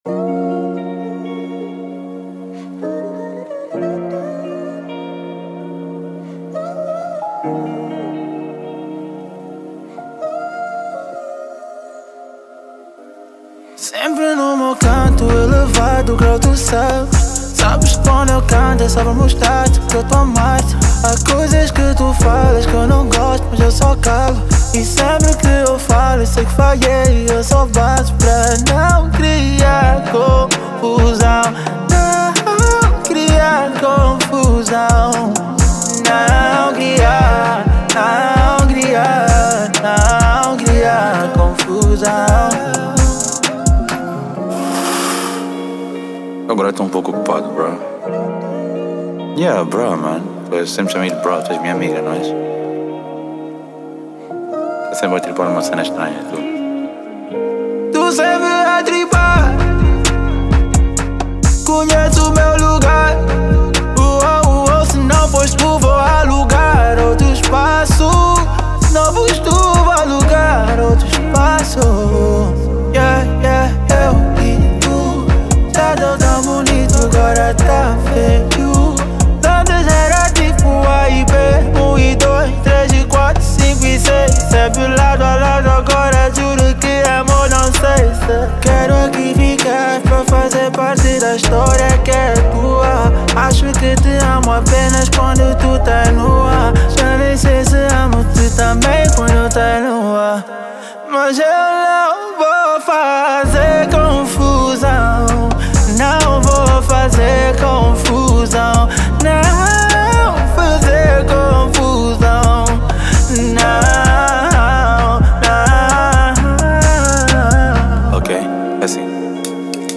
| RnB